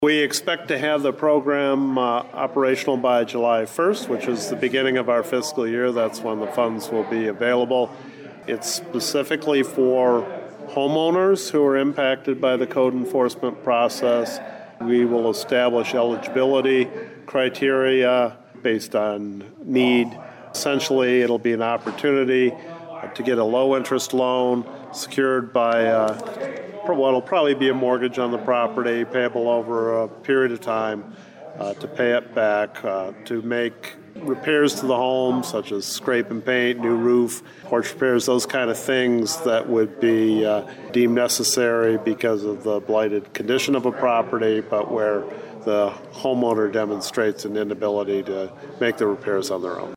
Adrian City Administrator Greg Elliott outlined what the $250,000 fund will do to help homeowners who are dealing with code enforcement issues…